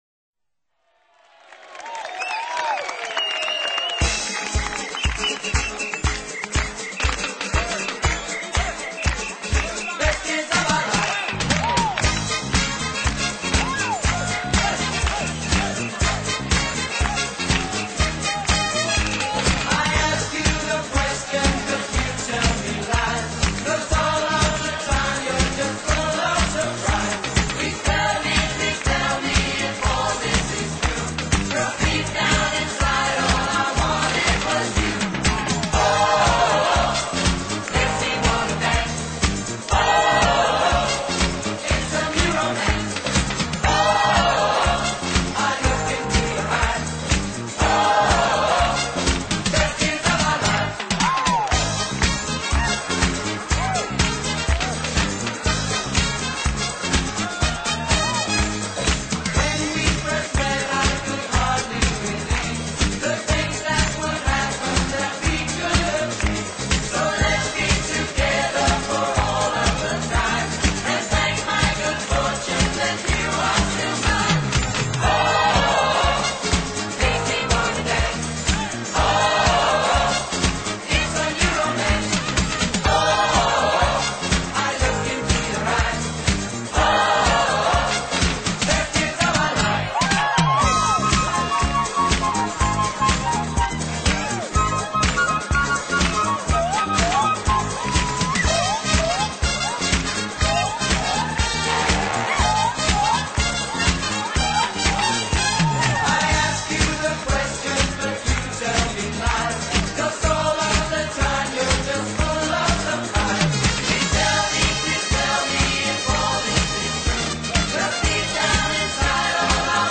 【轻音乐】
悉的歌曲，以轻快的跳舞节奏加以演奏，结果深受欢迎。